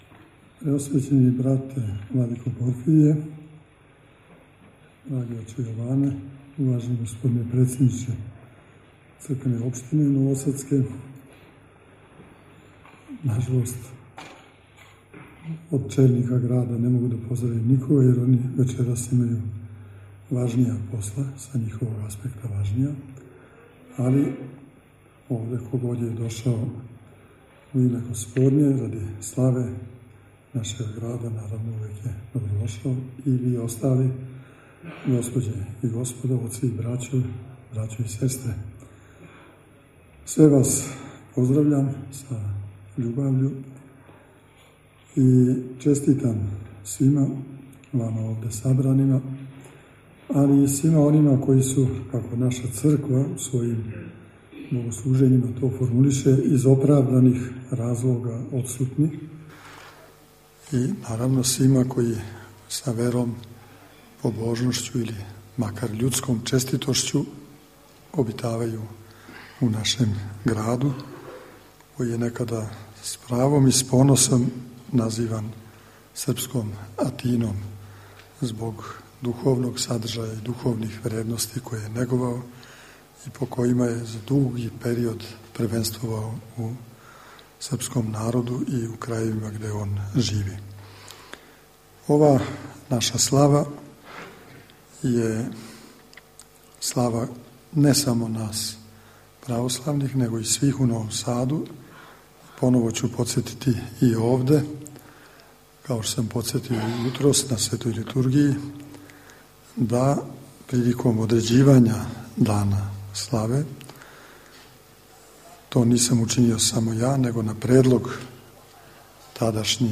• Беседа Епископа Иринеја: